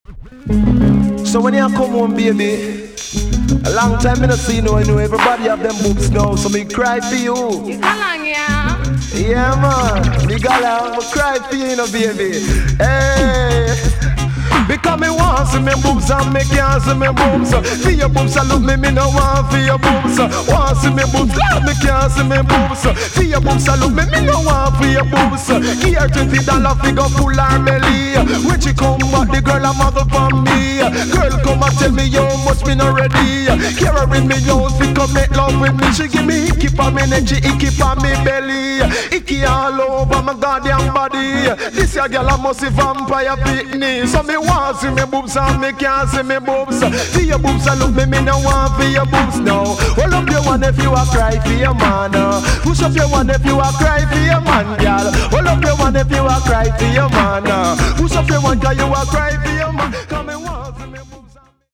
TOP >80'S 90'S DANCEHALL
EX-~VG+ 少し軽いチリノイズがありますがキレイです。